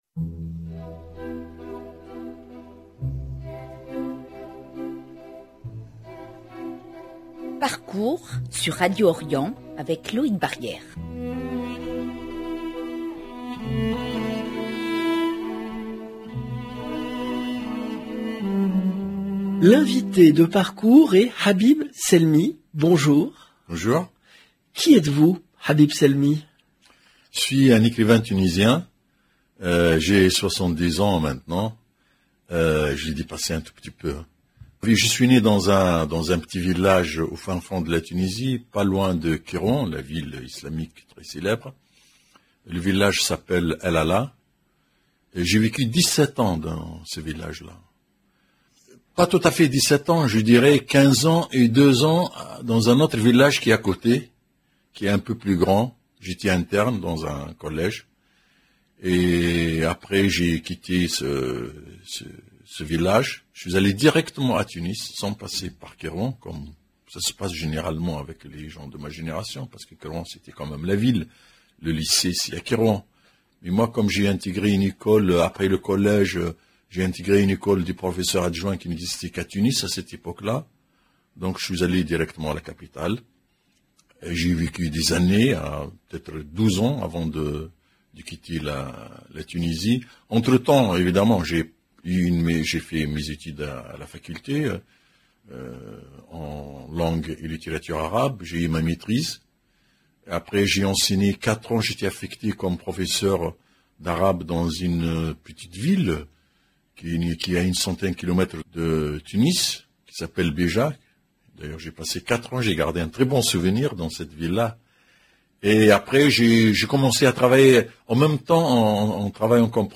Habib Selmi est l'invité de Parcours
Habib Selmi Parcours 24 juillet 2022 - 37 min 36 sec Habib Selmi est l'invité de Parcours LB PARCOURS L'écrivain tunisien de langue arabe Habib Selmi est l'invité de Parcours Emission diffusée le dimanche 24 juillet 2022 0:00 37 min 36 sec